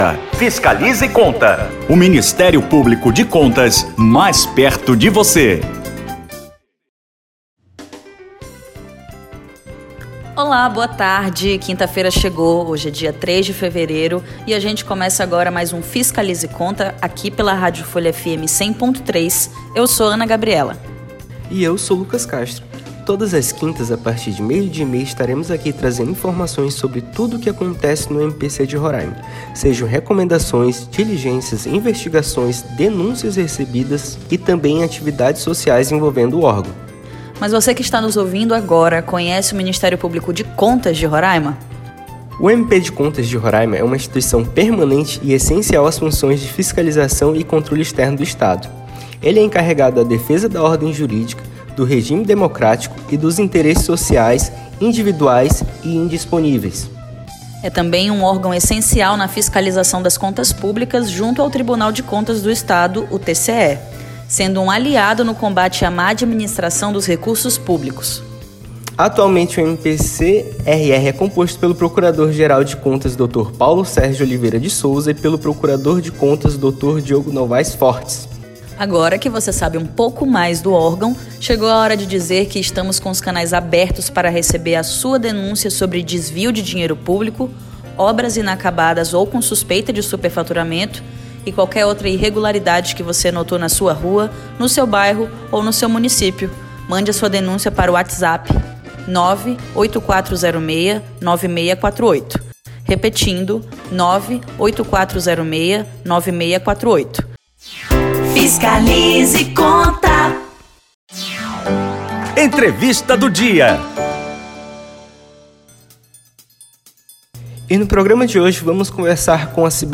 A procuradora-geral de Contas de Santa Catarina, Cibelly Farias, concede entrevista ao programa Fiscaliza e Conta, pela rádio Folha FM 100.3
A procuradora-geral do Ministério Público de Contas de Santa Catarina e Presidente do CNPGC, Cibelly Farias, concedeu hoje (03/02), às 12h30, entrevista para o programa Fiscaliza e Conta.